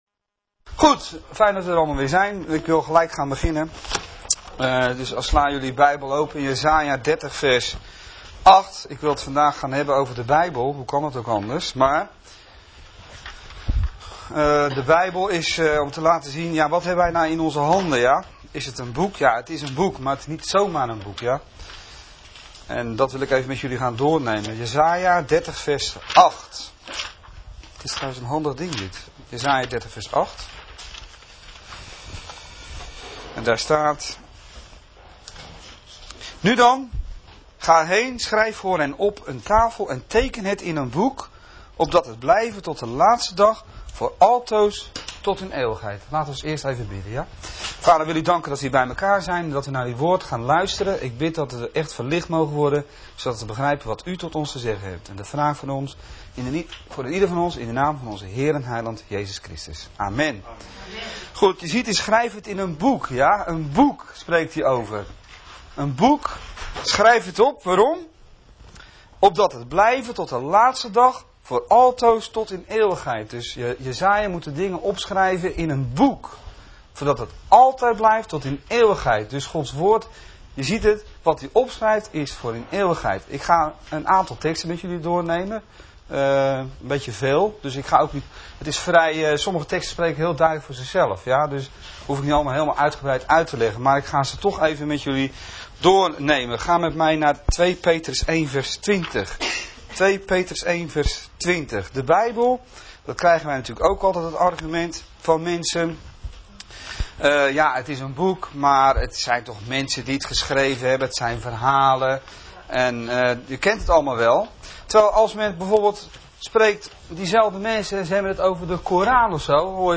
Lees.... hierbij de vergelijkende Bijbel teksten en aanttekeningen van deze preek.......